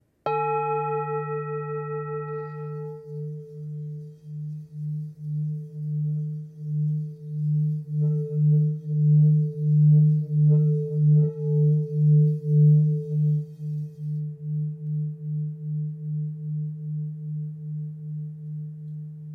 Tepaná tibetská mísa Chuto o hmotnosti 1 767 g, včetně paličky s kůží
Způsob provedení mísy: Tepaná
tibetska_misa_v16.mp3